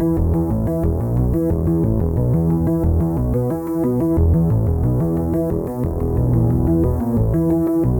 Index of /musicradar/dystopian-drone-samples/Droney Arps/90bpm
DD_DroneyArp2_90-E.wav